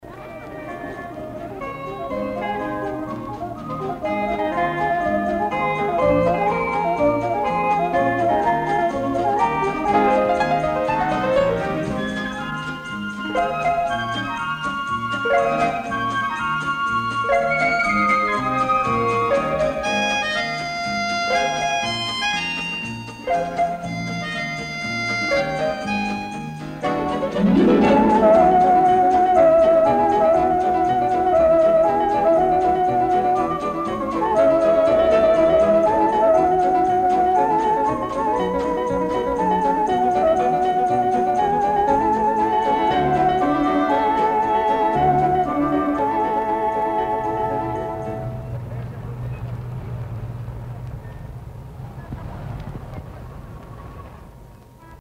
кроме эл. органа и эл. гитары